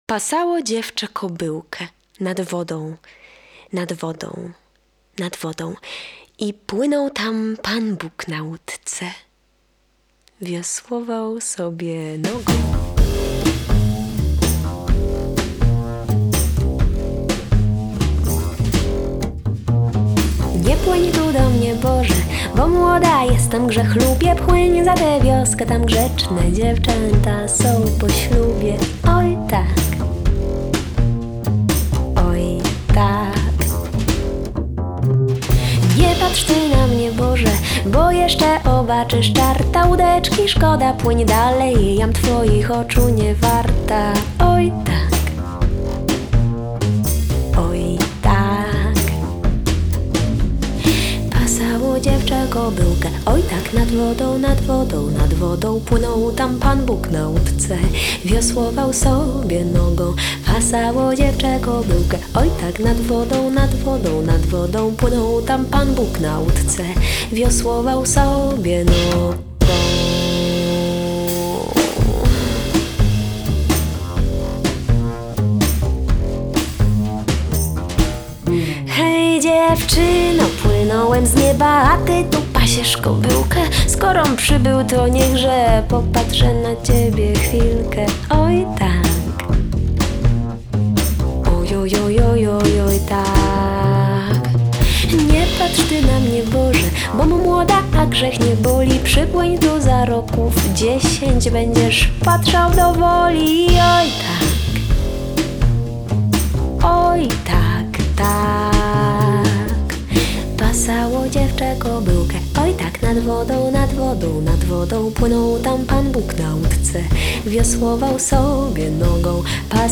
Genre: Vocal Jazz, Avant-Garde, Folk
klarnet, drumla, śpiew (clarinet, jaws harp, vocal)
kontrabas (double bass)